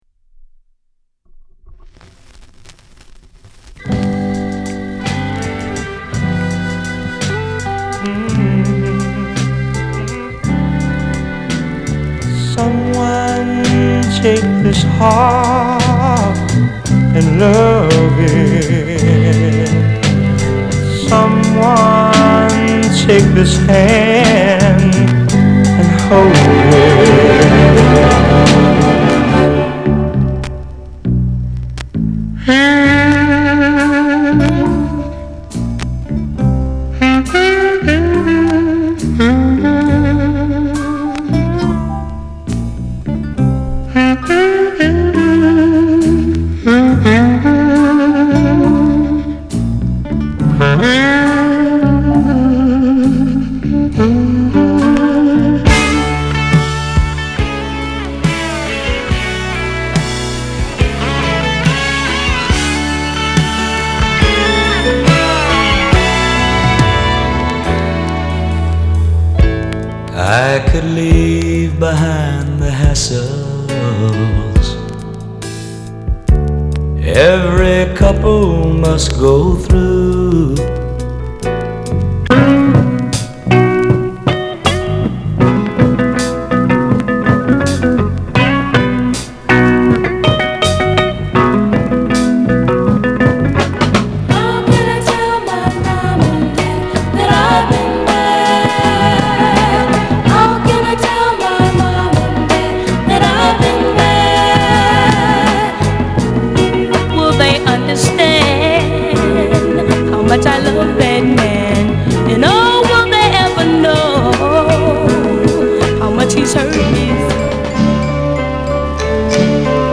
category Blues